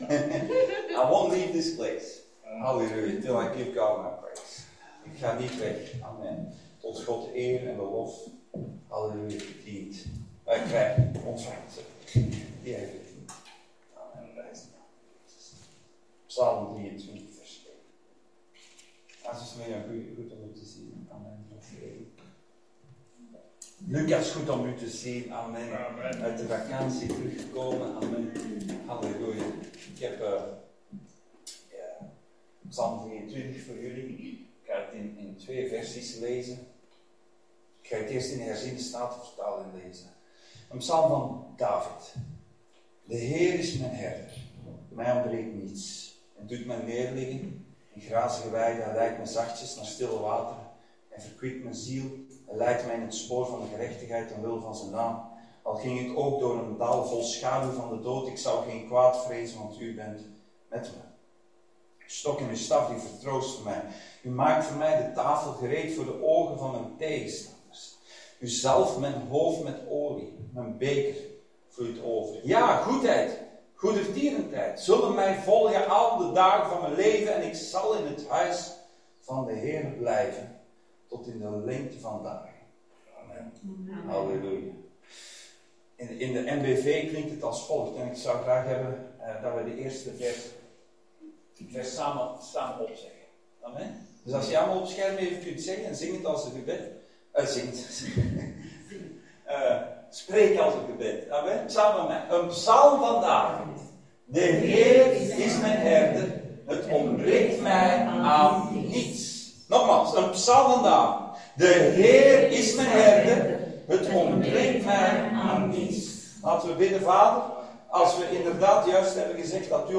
DeHeerismijnHerder Dienstsoort: Zondag Dienst « Salomon